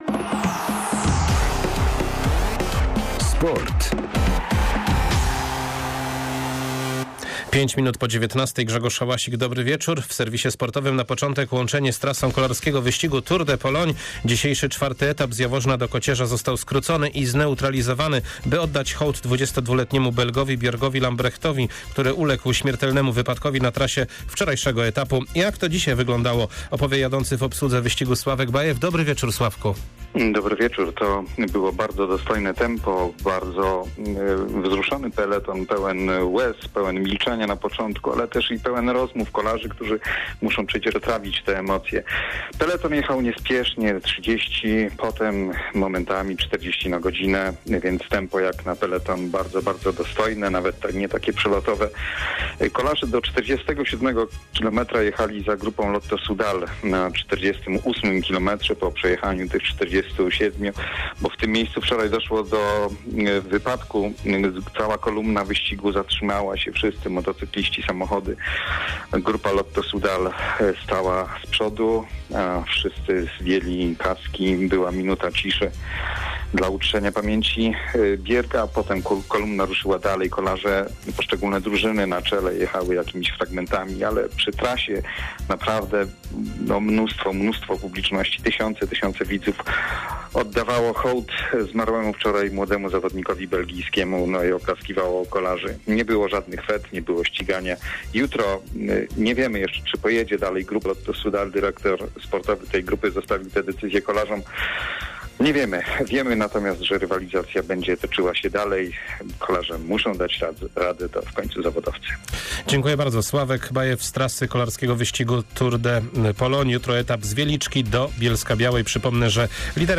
06.08. serwis sportowy godz. 19:05